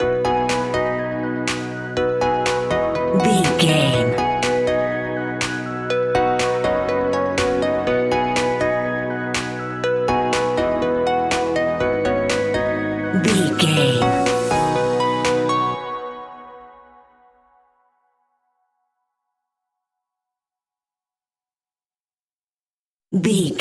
Ionian/Major
groovy
uplifting
driving
energetic
bouncy
synthesiser
drum machine
strings
electric piano
electronic
synth leads
synth bass